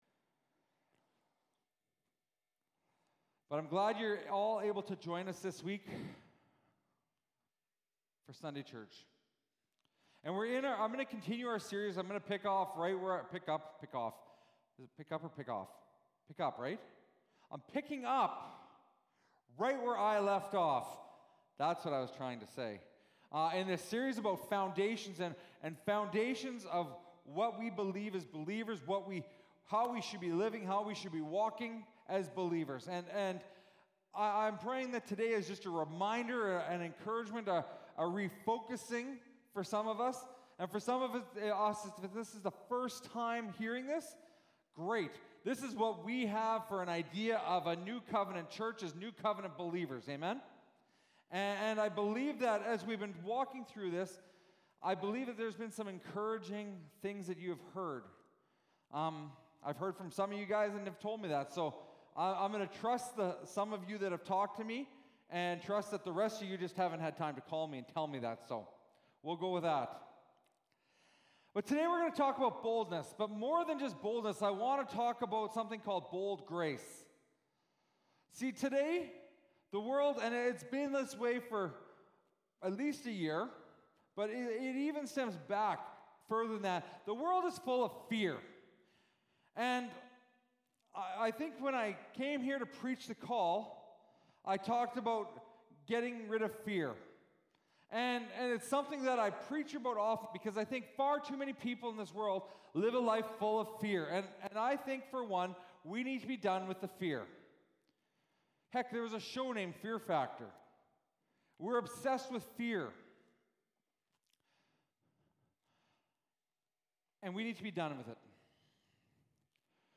Sermons | Rosetown Community Church